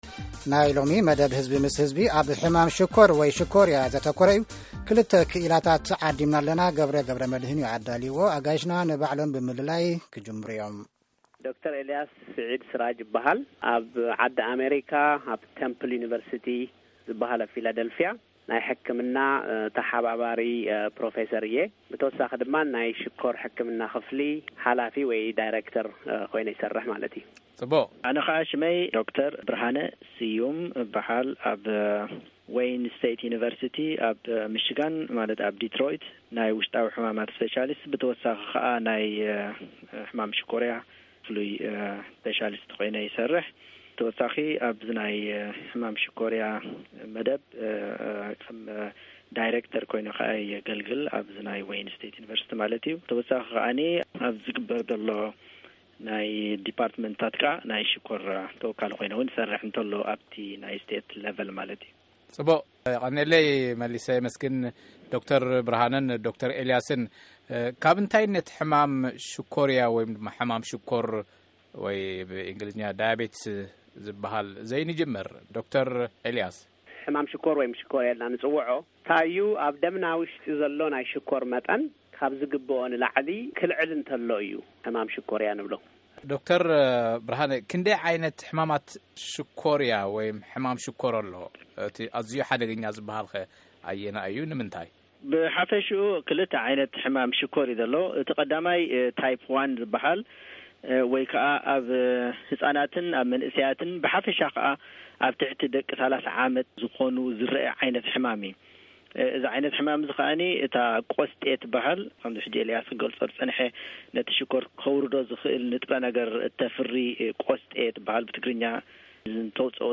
ብዛዕባ ሕማም ሽኮር ሓቲትናዮም ኣለና።